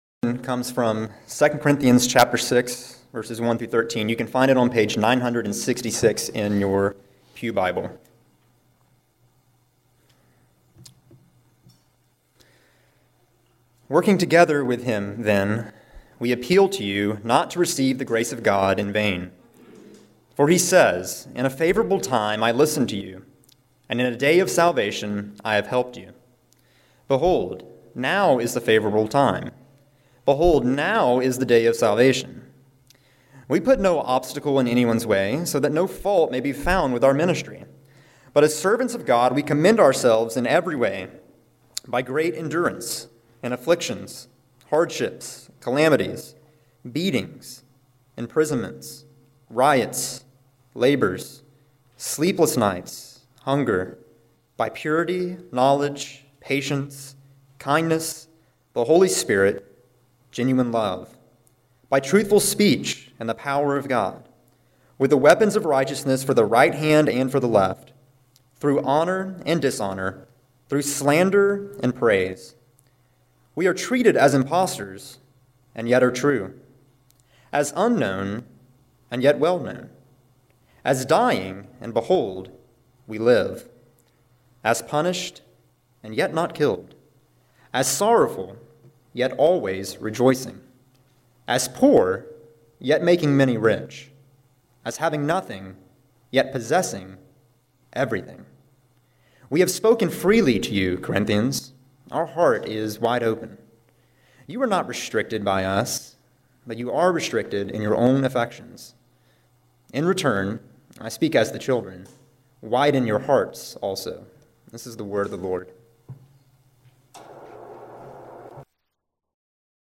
March 26, 2017 Morning Worship | Vine Street Baptist Church